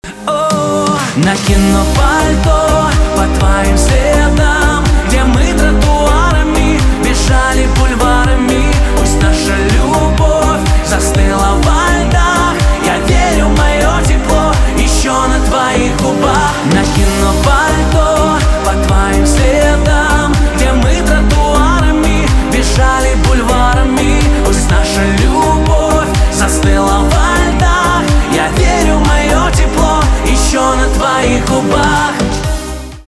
поп
чувственные
битовые , грустные